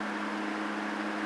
airship_fly.wav